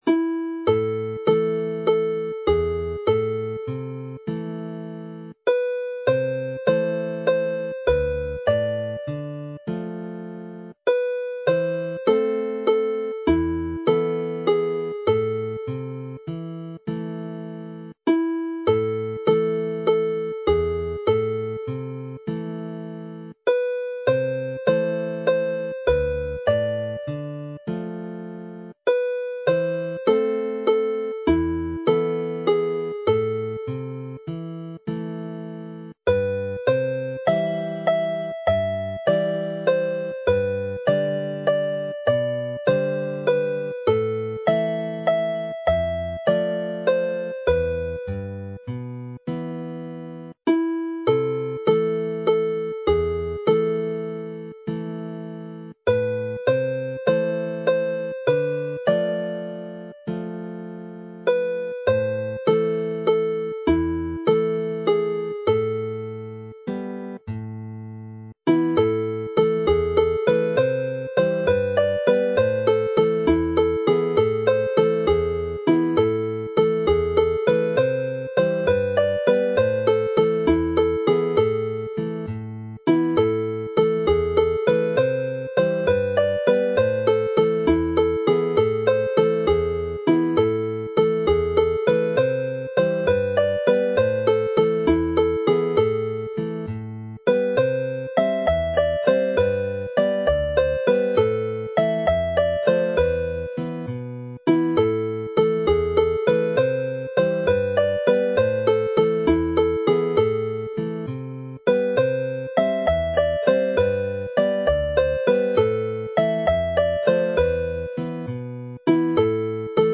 Play the set slowly